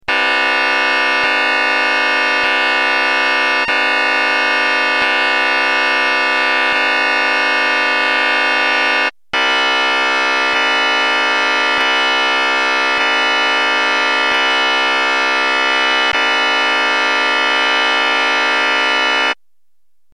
majminxenharmonic.mp3